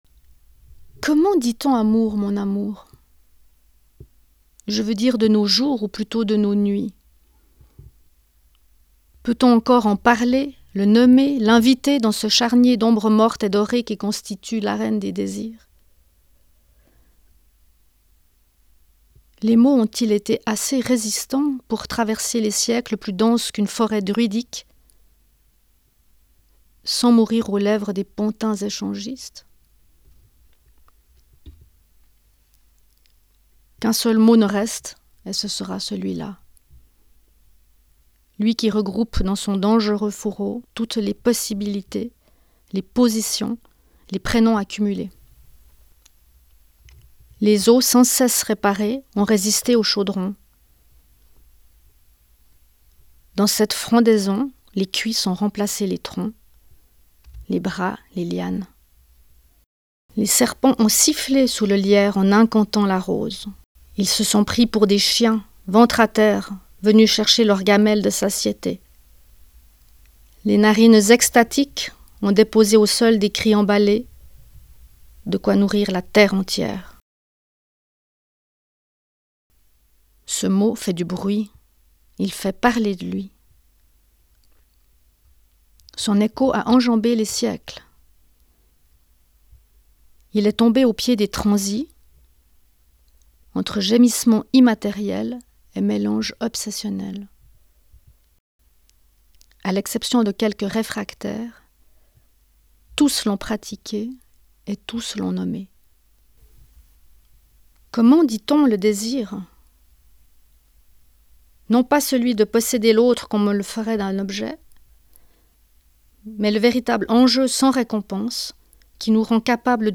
Lecture_spectacle_Marguerite-Burnat-Provins_1.m4a